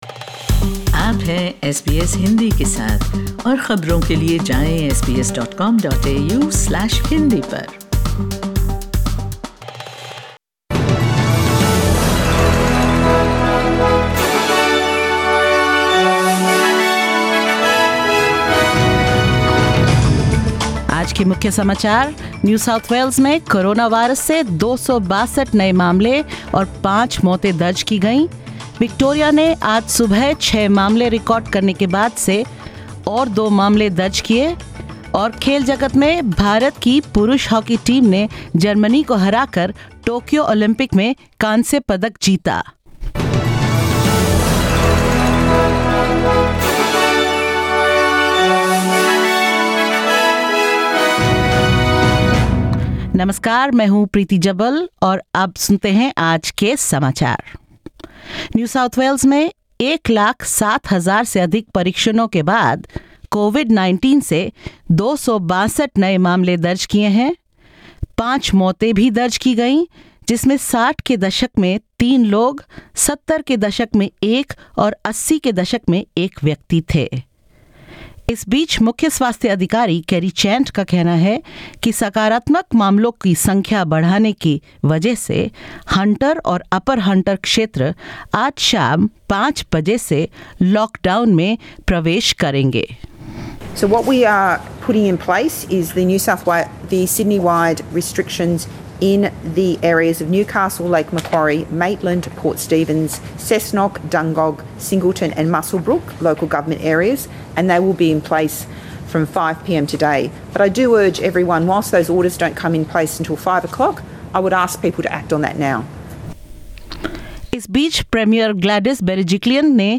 In this latest SBS Hindi News bulletin of Australia and India: New South Wales records its worst day with 262 new local COVID-19 cases and five deaths; Victoria enters another snap week-long lockdown after eight new cases and India claims bronze medal in men's hockey at the Tokyo Olympics and more.